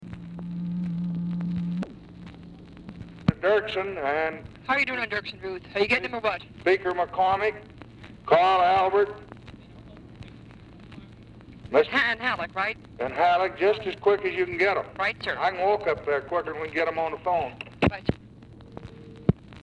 Telephone conversation
Format Dictation belt
White House Telephone Recordings and Transcripts Speaker 1 LBJ Speaker 2 TELEPHONE OPERATOR